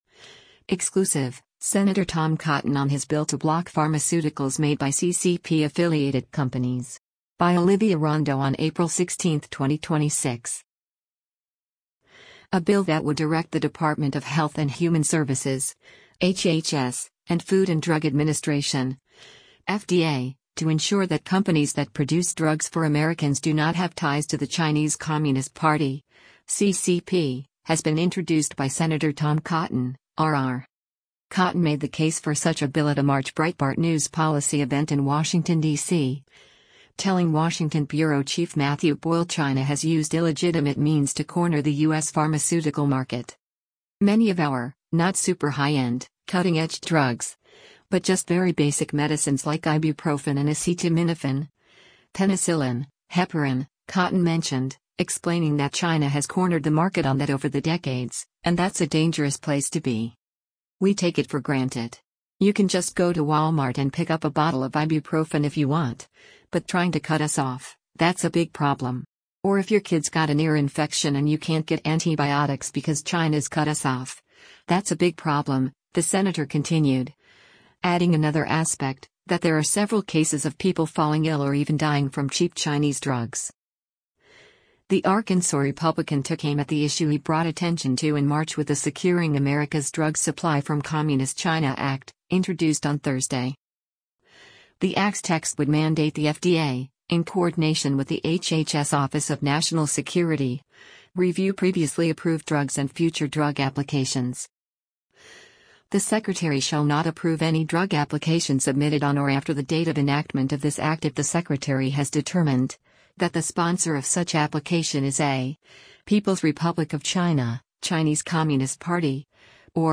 Sen. Tom Cotton (R-AR) speaking at a Breitbart News policy event on March 26, 2026, in Was